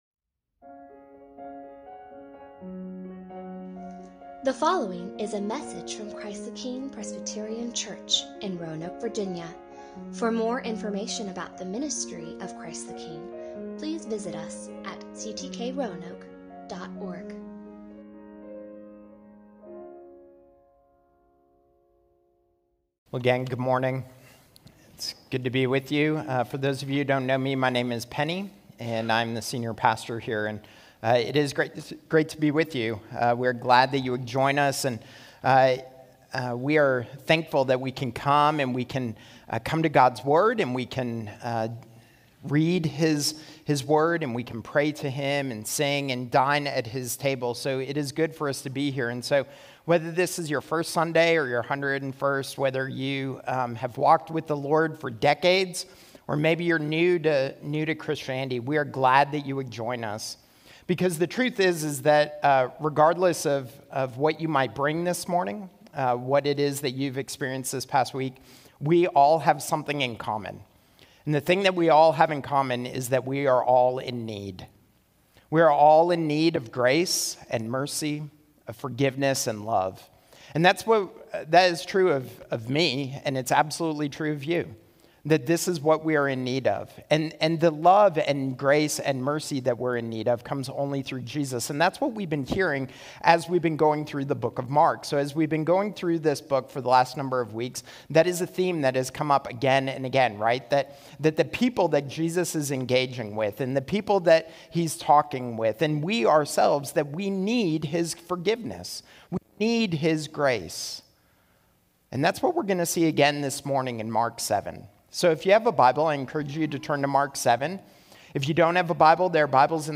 This is a sermon on Mark 7:24-37.